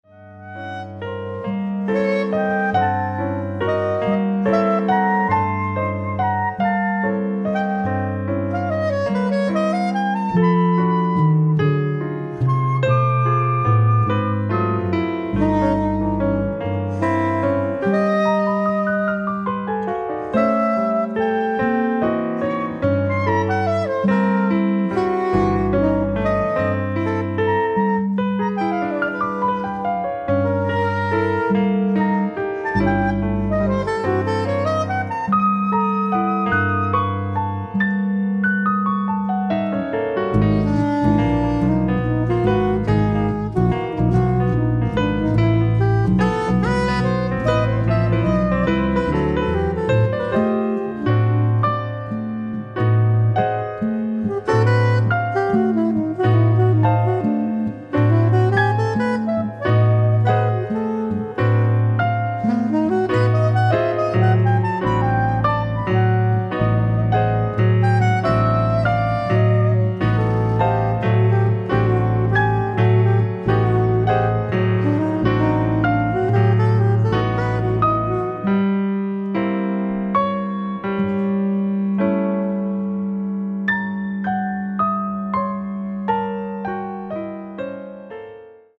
ライブ・アット・ビムハウス、アムステルダム、オランダ 07/15/2016
放送用音源から最高音質で収録！！
※試聴用に実際より音質を落としています。